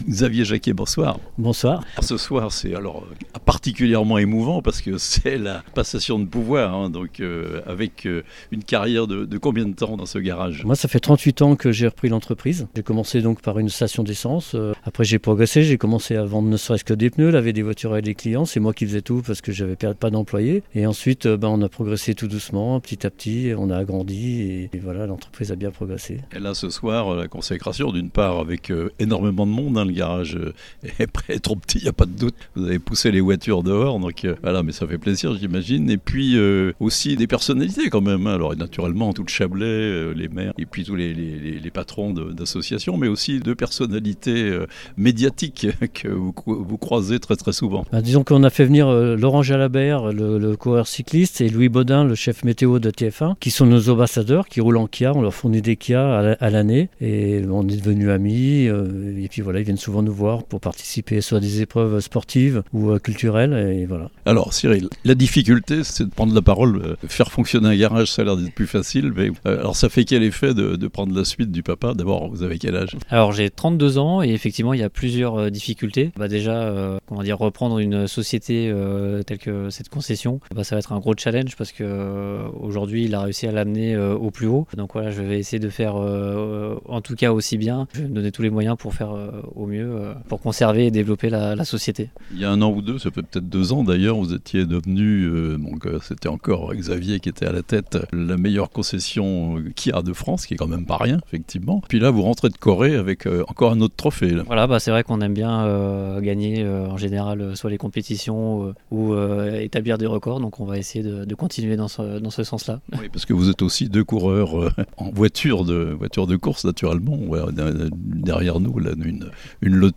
Changement de direction à la tête de l’une des plus anciennes entreprises de la zone artisanale de Margencel (interviews)